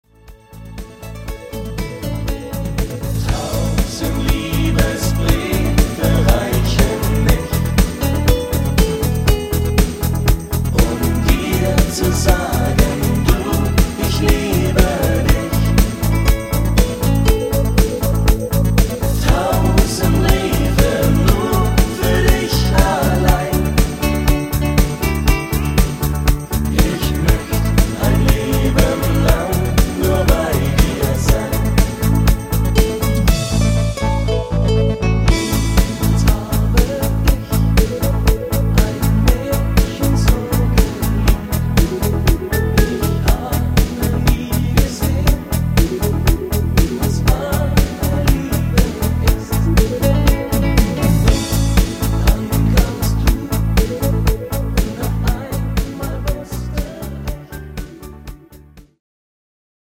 Partymix